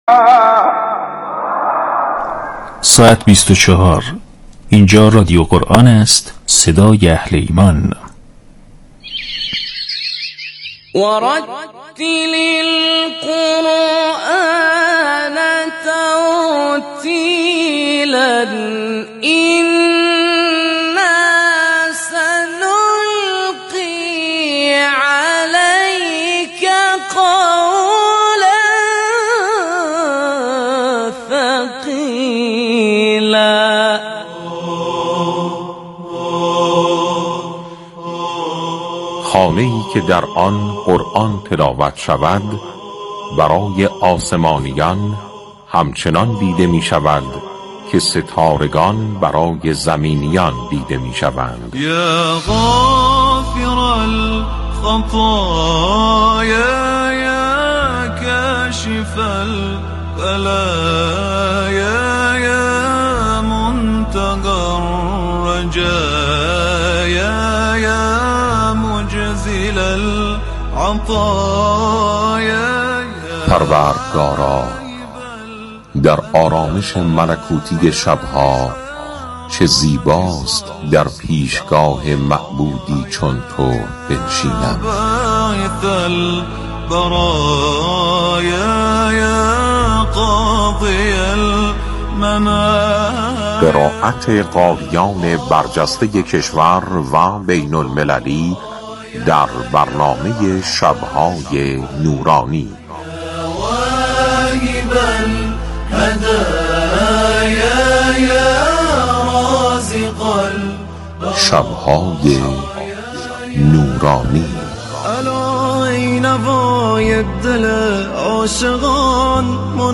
شب‌های نورانی عنوان برنامه جدید رادیو قرآن است که با محوریت آشنایی با فعالان قرآنی استان‌ها و معرفی مهمترین رویدادهای قرآنی هر استان به صورت زنده، تقدیم شنوندگان می‌شود.